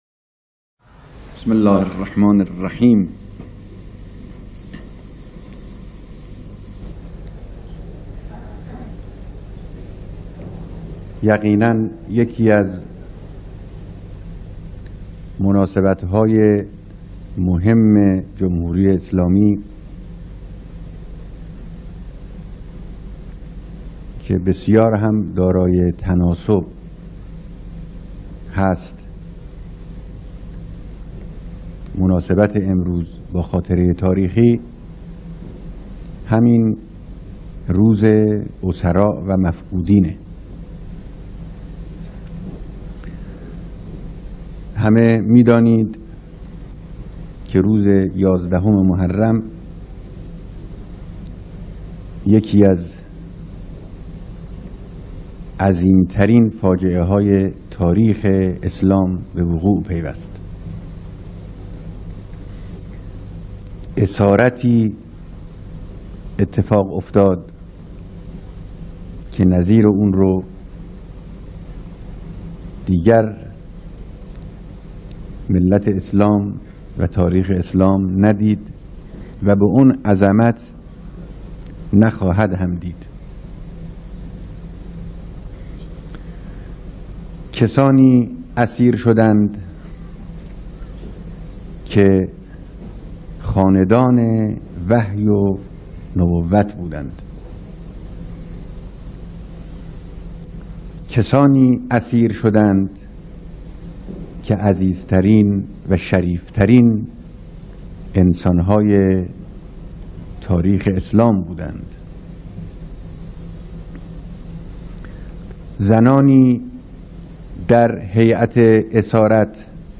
بیانات رهبر انقلاب در دیدار جمع کثیری از خانواده‌های معظّم شهدا و ایثارگران